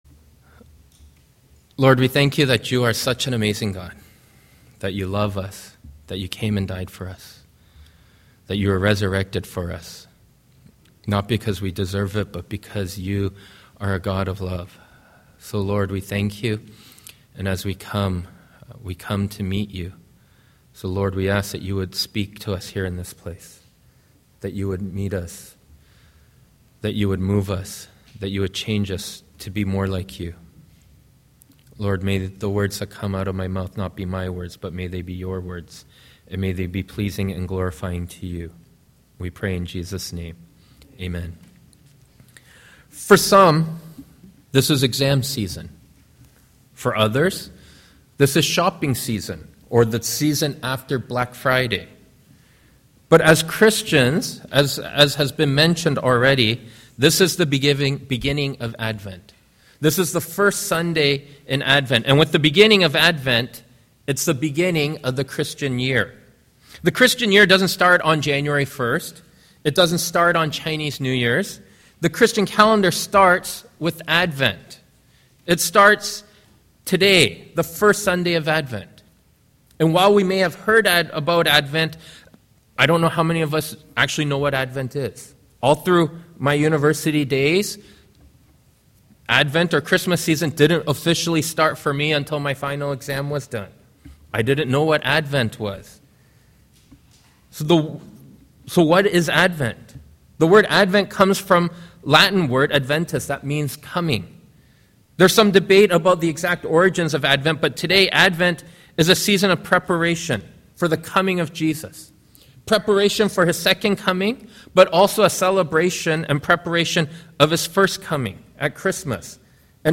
Sermons | Lord's Grace Church